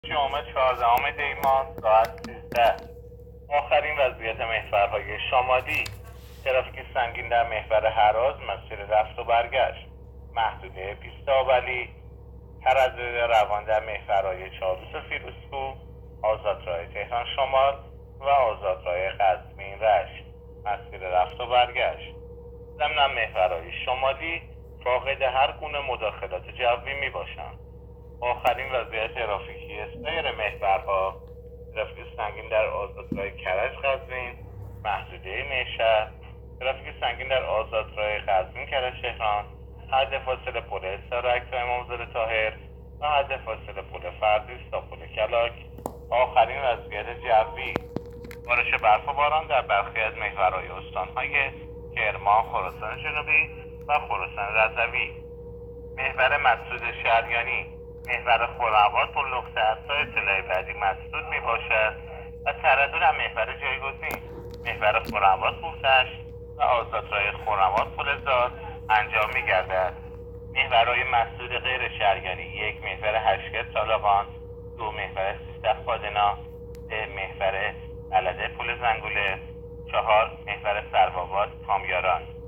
گزارش رادیو اینترنتی از آخرین وضعیت ترافیکی جاده‌ها تا ساعت ۱۳ چهاردهم دی؛